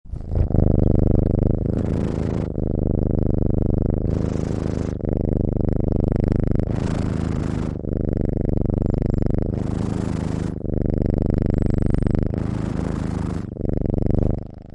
Cat Purring 2 Sound Button - Free Download & Play
Cat Sounds725 views